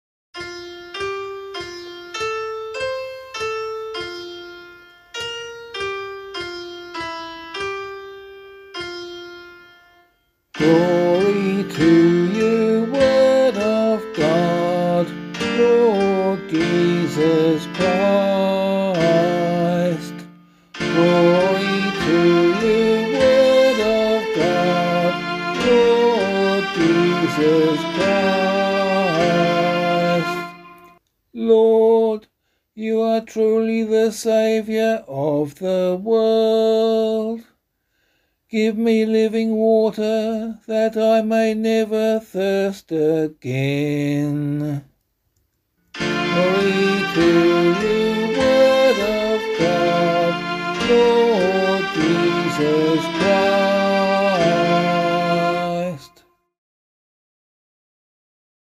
Gospelcclamation for Australian Catholic liturgy.